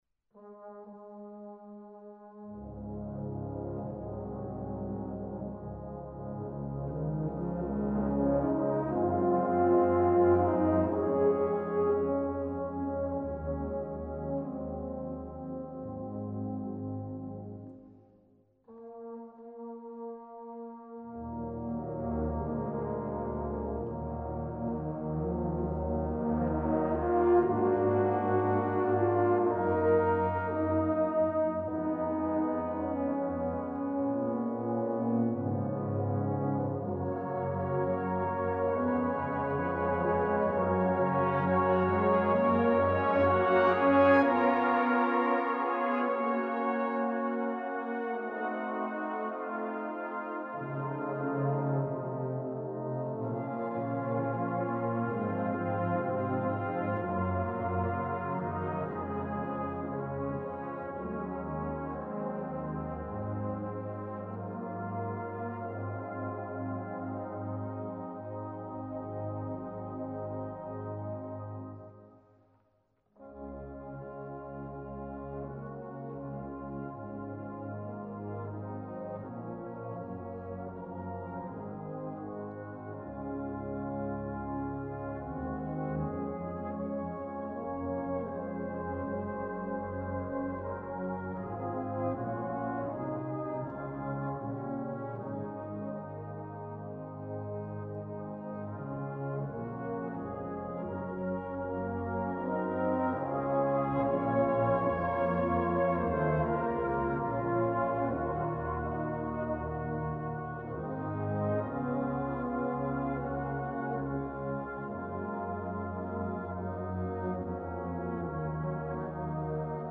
band piece